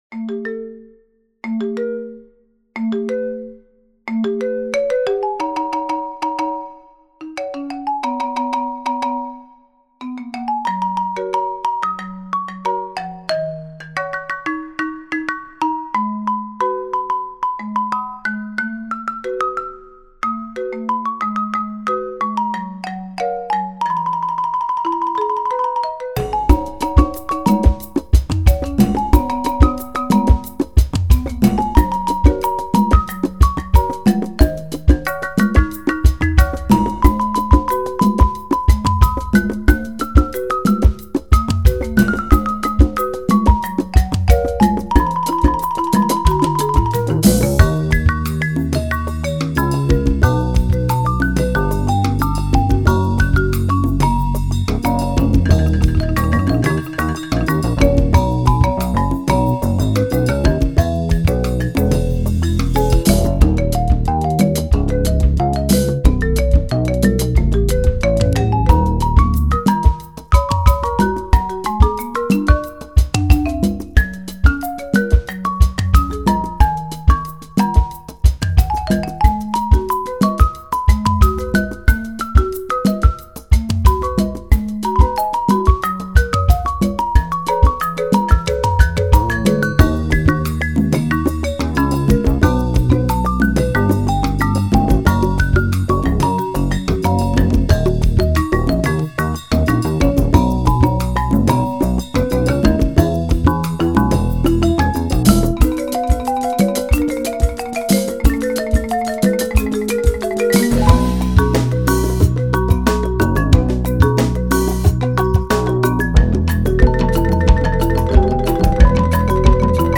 for Two Marimbas, Percussion and Bass
Scored in a lively Latin/Rock groove for two marimbas